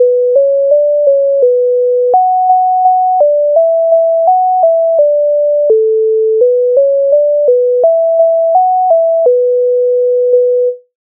MIDI файл завантажено в тональності h-moll
Ой у полі та туман димно Українська народна пісня з обробок Леонтовича c.135
Ukrainska_narodna_pisnia_Oj_u_poli_ta_tuman_dymno.mp3